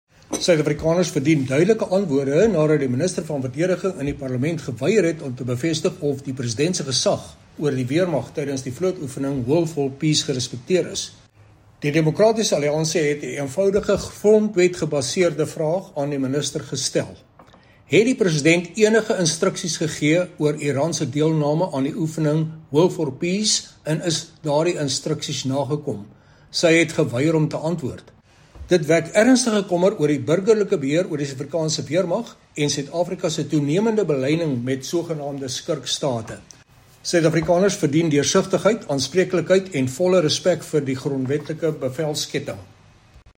Issued by Chris Hattingh MP – DA Spokesperson on Defence & Military Veterans
Afrikaans by Chris Hattingh MP.